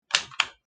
flashlight.CknC8BZr.mp3